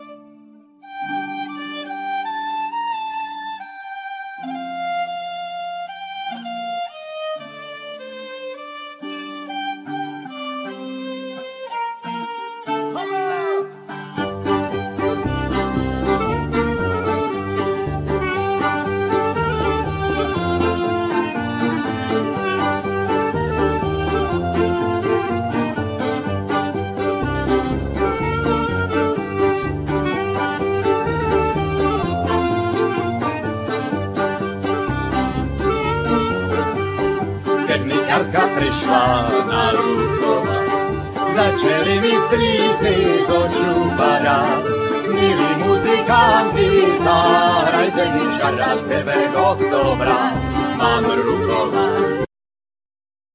Flute,Percussions,Gittern,Vocals
Gittern,Saz,Tarabuka,Vocals
Five strings fiddle,Percussions,Vocal
Soprano fiddle,Tarabuka,Vocal
Davul,Tamburello,Trabuka,Vocal
Double bass